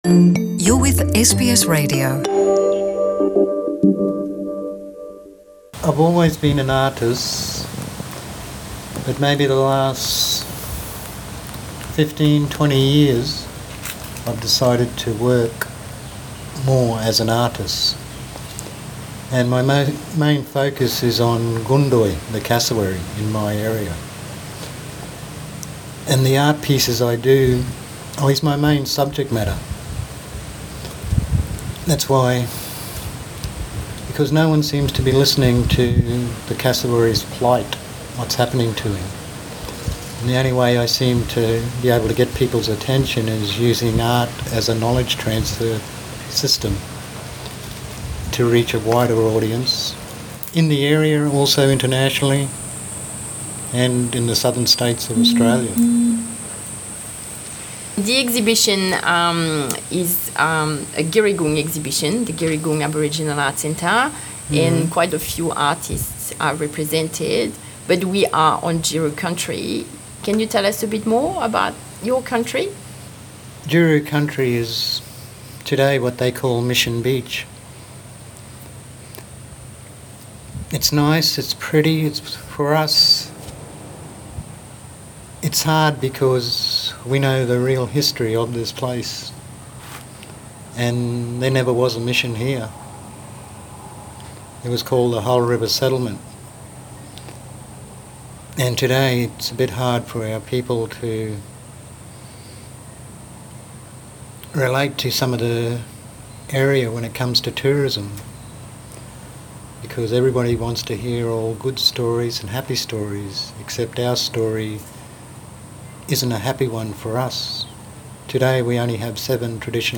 In an interview with NITV Radio